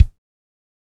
Index of /90_sSampleCDs/Northstar - Drumscapes Roland/KIK_Kicks/KIK_A_C Kicks x
KIK A C K0EL.wav